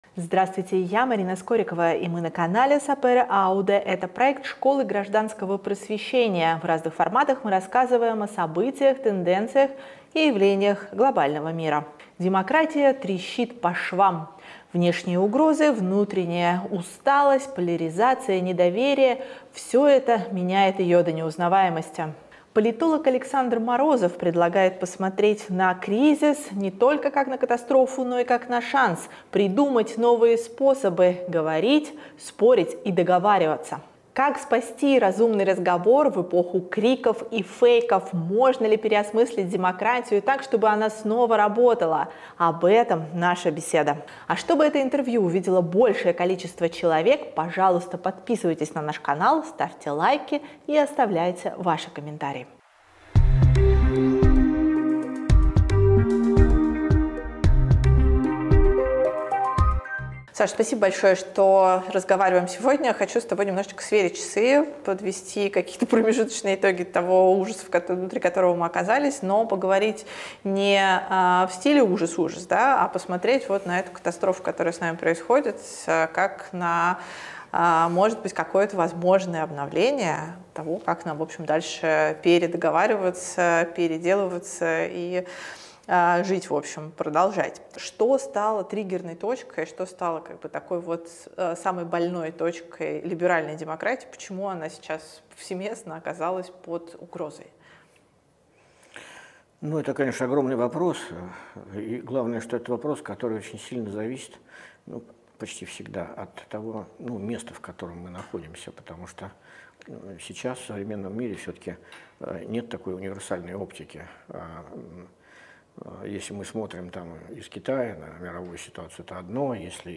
Как спасти разумный разговор в эпоху криков и фейков? Можно ли переосмыслить демократию так, чтобы она снова работала? Об этом — наш разговор.